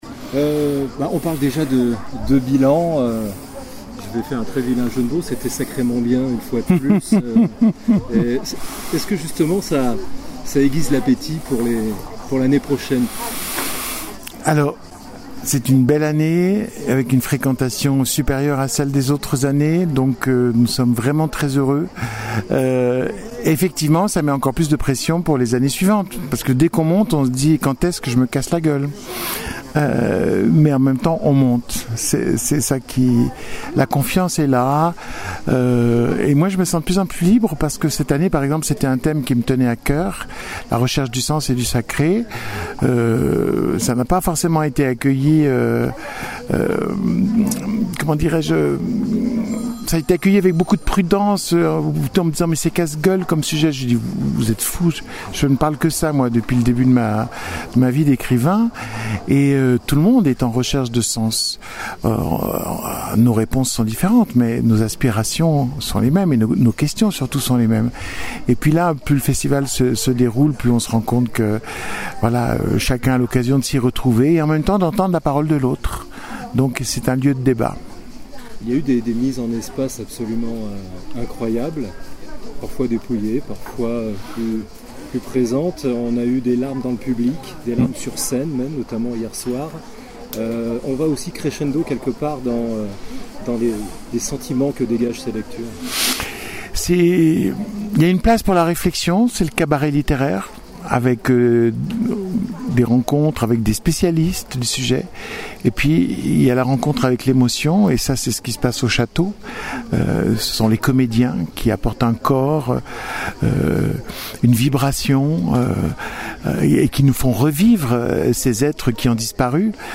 Dans un entretien-bilan à l’issue de cette dernière édition, Eric Emmanuel SCHMITT revient sur une année marquée par l’intensité des émotions, une fréquentation record, et une ambition renouvelée autour du sens et du sacré.
Le sourire dans la voix, le ton est celui d’un homme satisfait mais lucide.